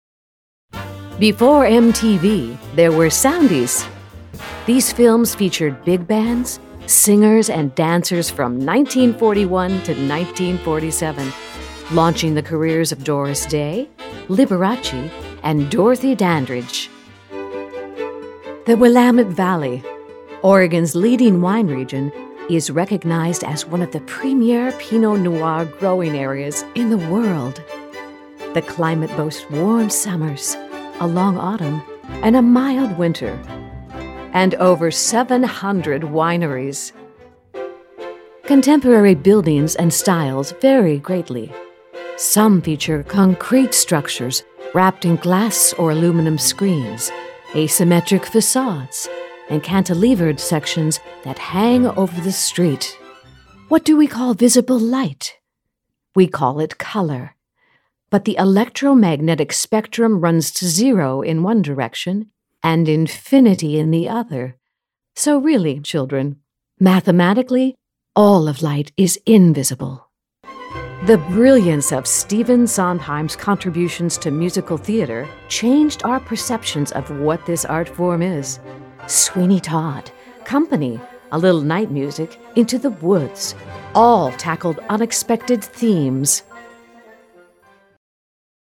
Vibrant and Talented Actor, Singer Dancer and VO talent.
Irish British Russian
Voice Age
Middle Aged